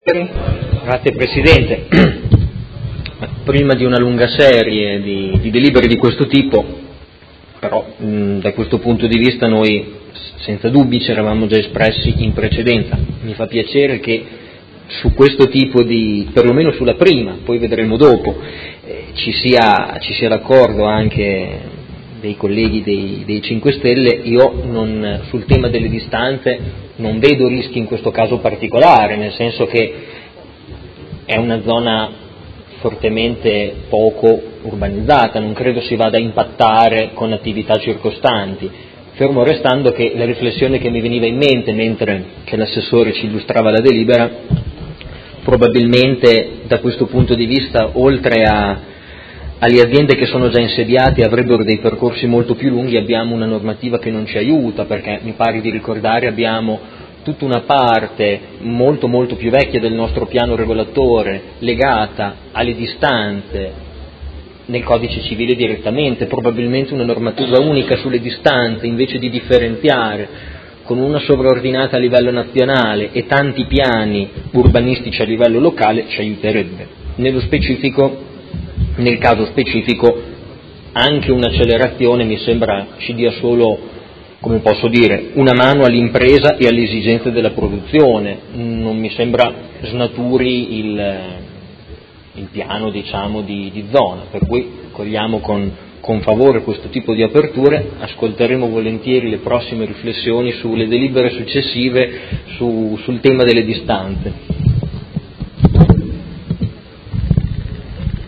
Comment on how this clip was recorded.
Seduta del 13/07/2017 Dibattito.